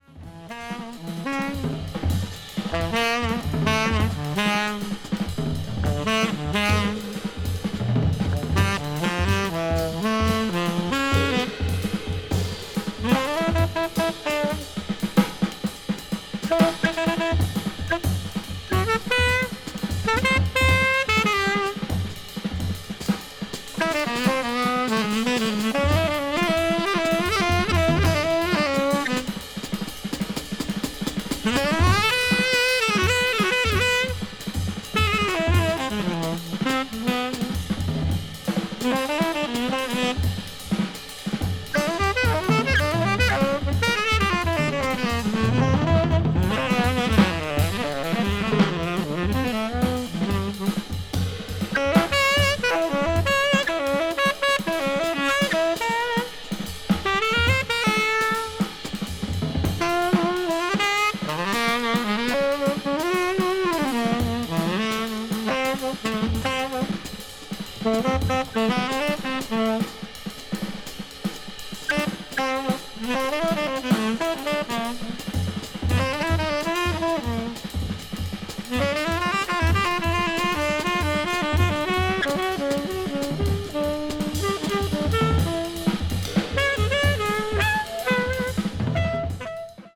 avant-jazz   free improvisation   free jazz   post bop